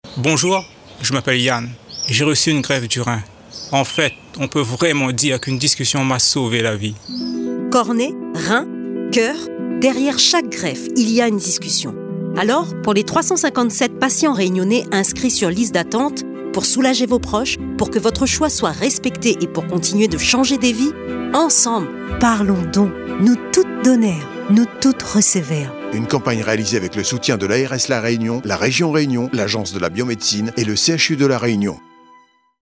La campagne de communication avec les 3 témoignages se décline :
en spots radio